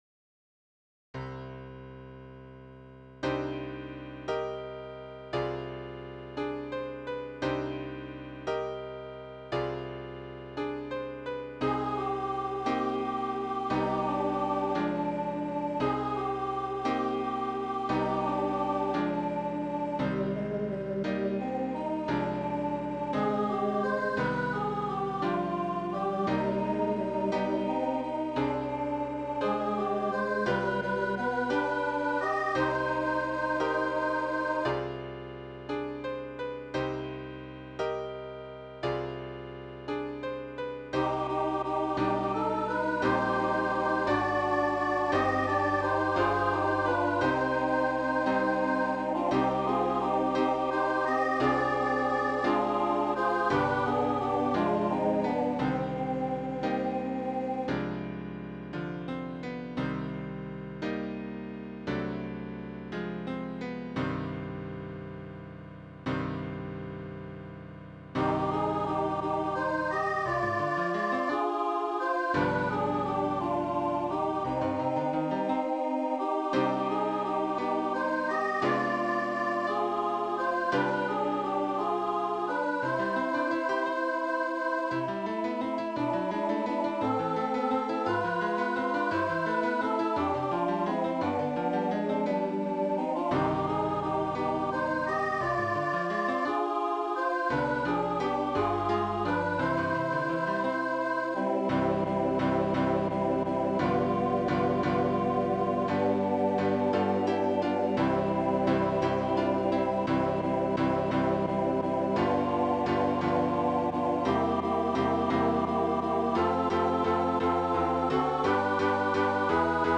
Voicing/Instrumentation: SSA We also have other 27 arrangements of " Master, the Tempest Is Raging ".